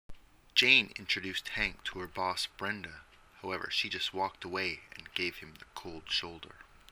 英語ネイティブによる音声はこちらです。